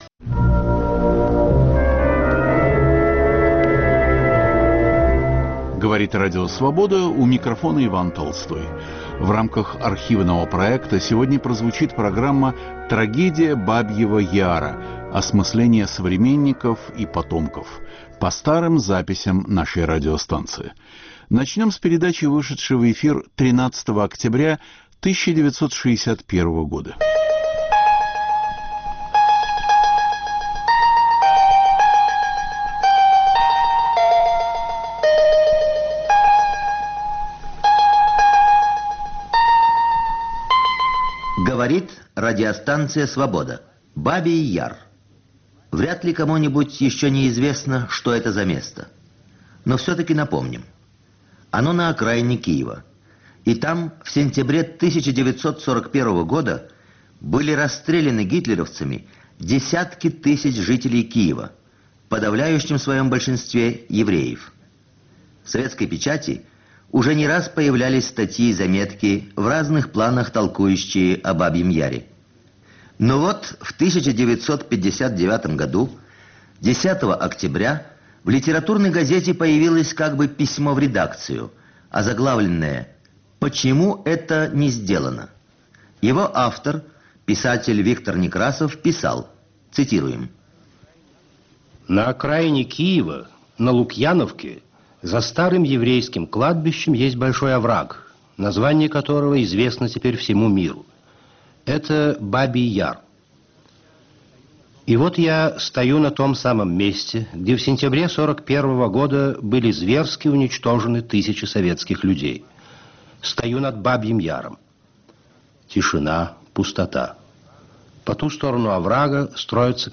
75 лет трагедии Бабьего Яра. В рамках архивного проекта - подборка интервью, выступлений и чтений за разные годы вещания.